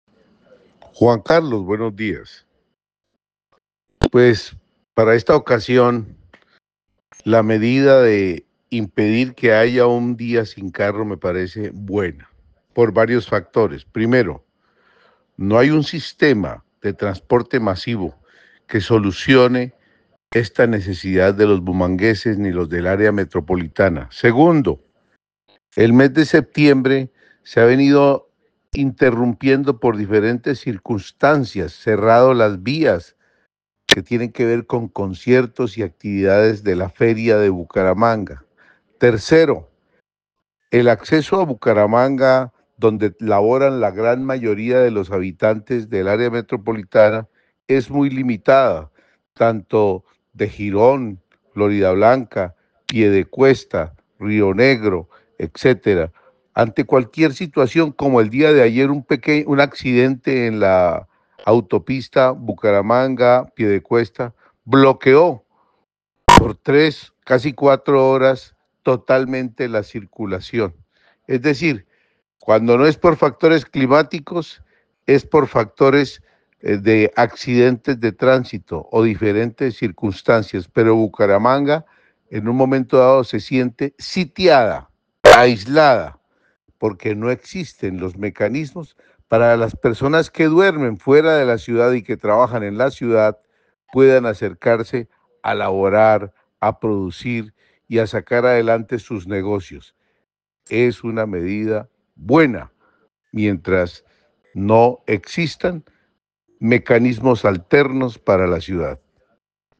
Opinión de un empresario de Bucaramanga